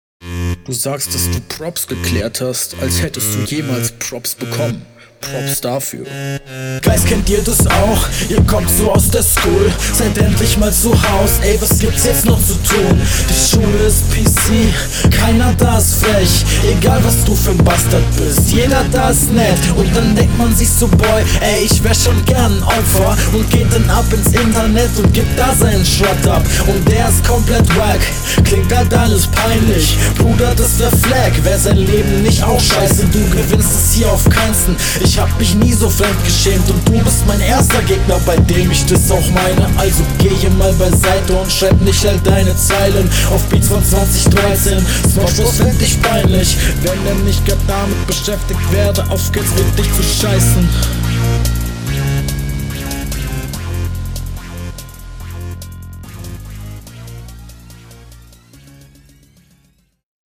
Rückrunde 1
Stimme und Flow ist schon von Anfang an deutlich besser als beim Gegner.